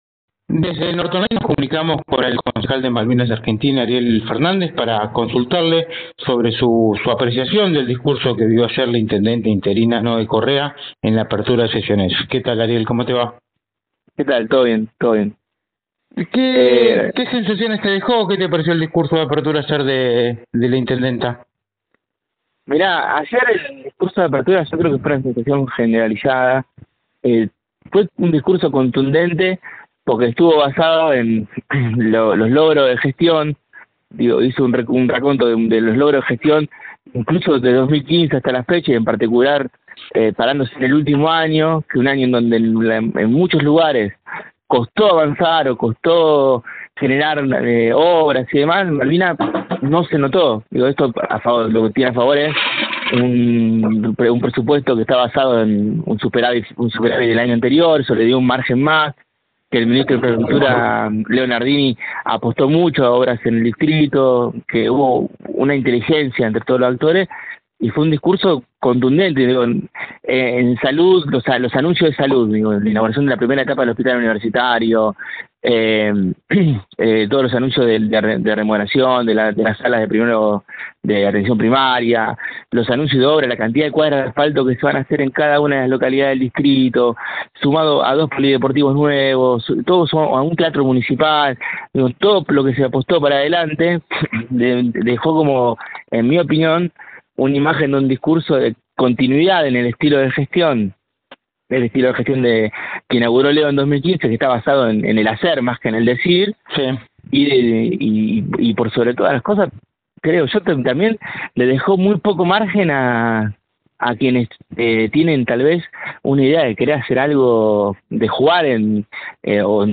El concejal del Frente de Todos en Malvinas Argentinas habló en exclusiva con NorteOnline acerca del futuro del distrito en el presente año electoral, en el marco de la apertura de sesiones ordinarias.